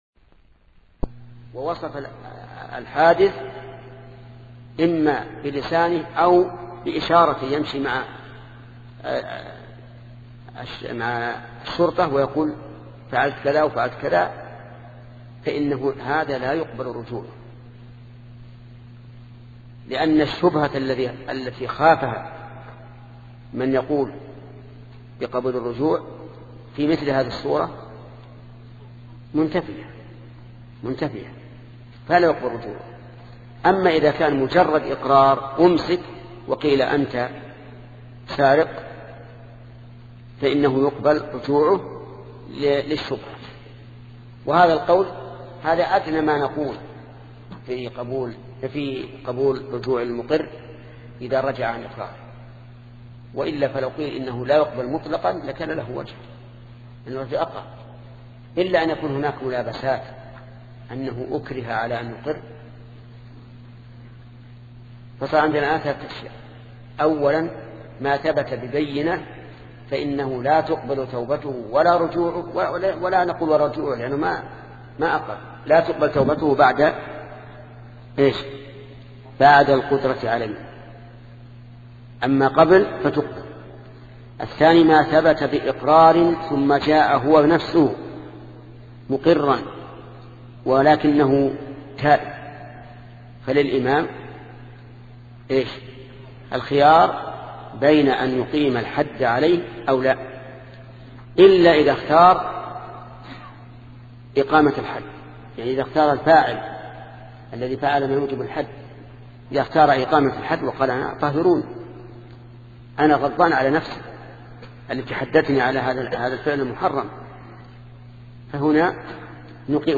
سلسلة مجموعة محاضرات التعليق على السياسة الشرعية لابن تيمية لشيخ محمد بن صالح العثيمين رحمة الله تعالى